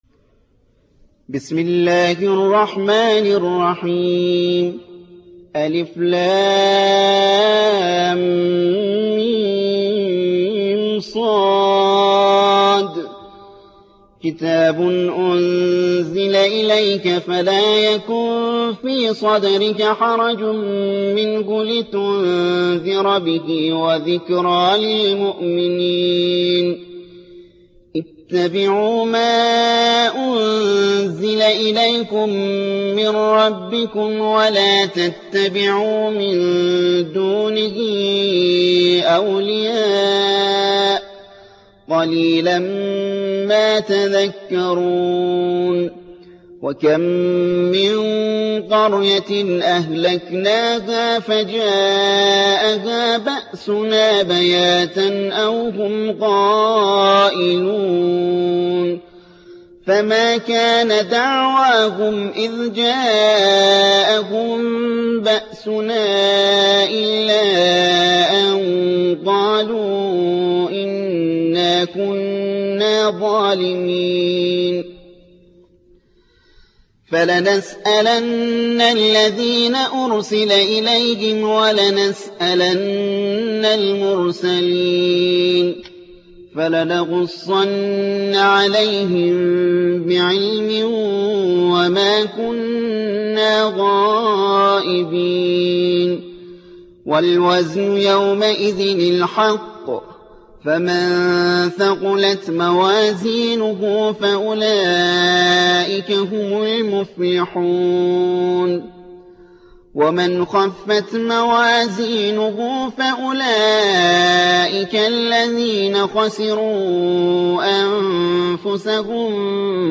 7. سورة الأعراف / القارئ